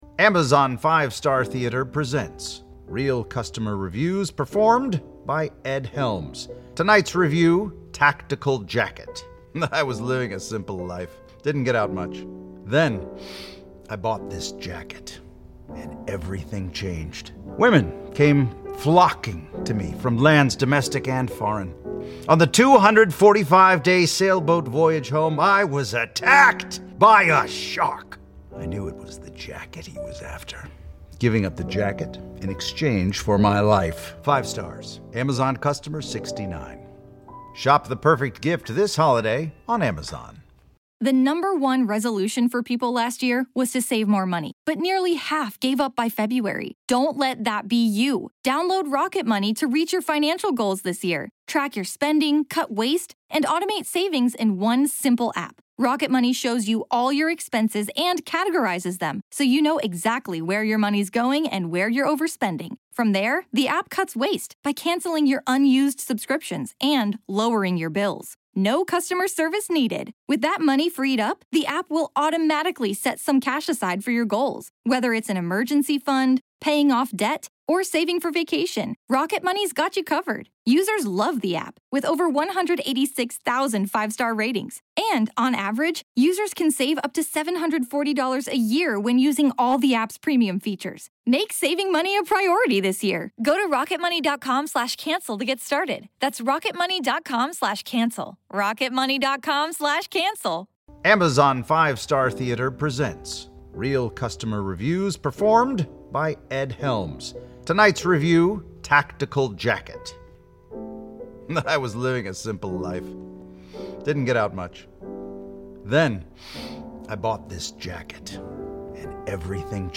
In this episode, we’ll continue our conversation about monsters.